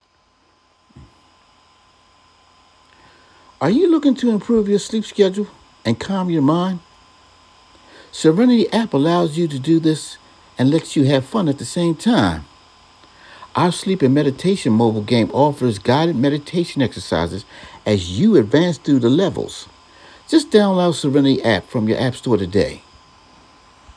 Clear, Concise, and Convincing
Demos
Urban
Middle Aged
3. CONVINCING and relatable delivery that inspires action